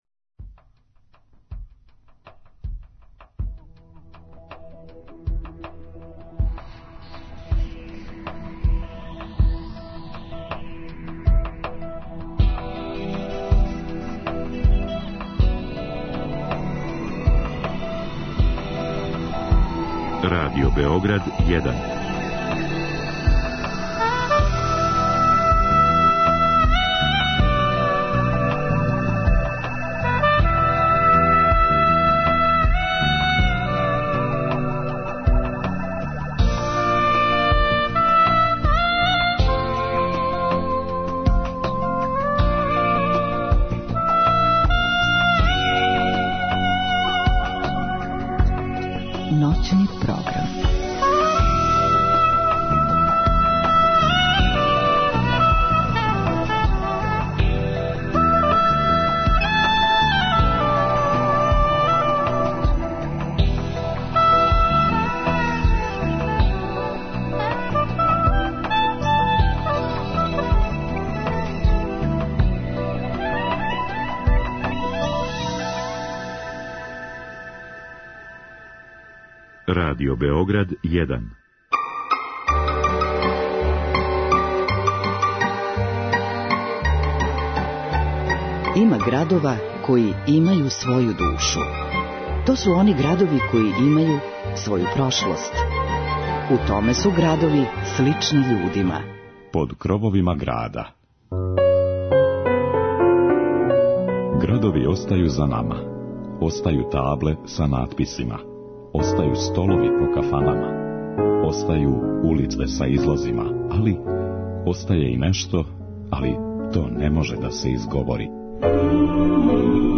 Поштовани слушаоци, ове ноћи прошетаћемо Сремом, музиком и причом обићи градове и села које припадају овој равничарској низији. Упознаћемо Вас са богатом историјом некадашњег Сирмијума и уз звуке тамбурашких оркестара слушати чувене Сремачке бећарце и врсне уметнике који их изводе.